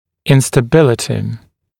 [ˌɪnstə’bɪlətɪ][ˌинстэ’билэти]нестабильность, неустойчивость